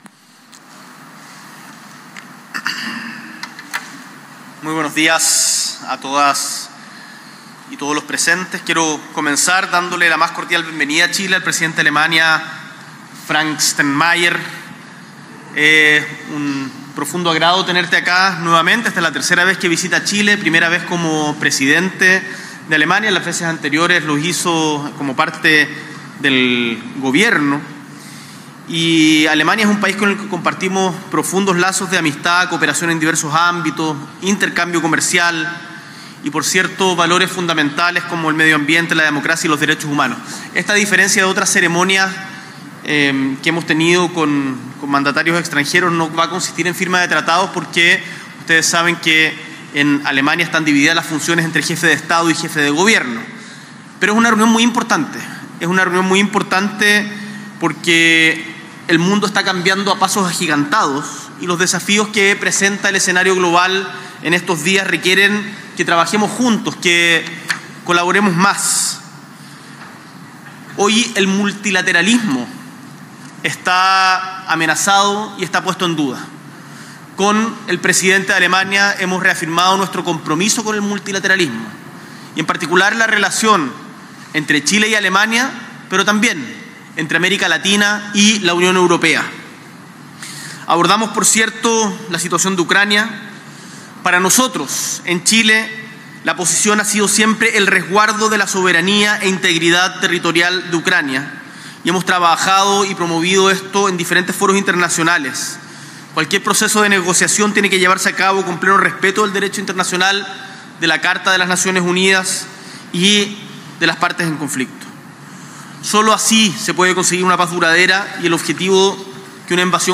S.E. el Presidente de la República, Gabriel Boric Font, realiza declaración conjunta con el Presidente de Alemania